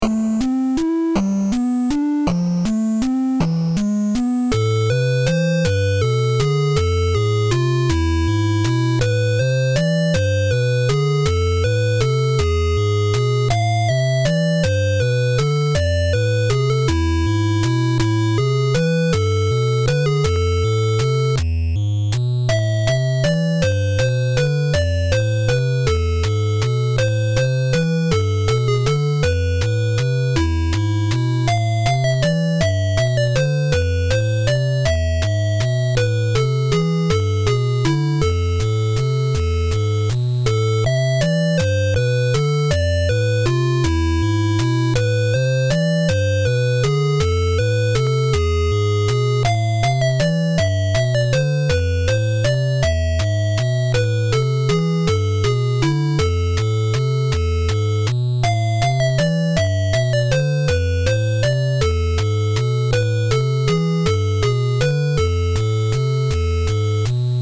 8-bit音乐的制作限制了乐器和音轨，通常用方波制作主弦律，三角波和弦，噪声波模仿鼓点。
看了看日期，写个有万圣节氛围的旋律吧。整首曲子用了3/4拍的A小调，主要的音都在白键上。
先写和弦渲染氛围：Am-G-F-E7，逐渐下行的旋律带来一种黑暗的，下降的感觉。
节奏部分使用了编辑器里的杂音。整首曲子使用了三拍子的节奏，选用了简单且有节奏感的“ABB”型，重复整首。
在旋律中使用一些E7和弦中的#G音，带有升降号的音为旋律带来诡异与紧张的感觉。